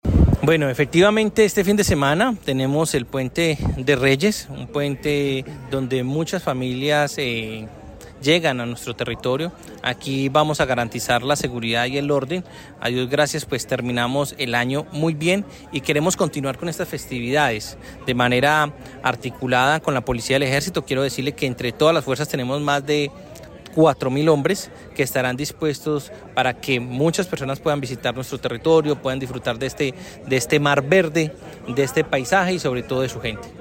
Secretario del Interior del Quindío, Jaime Andres Perez Cotrino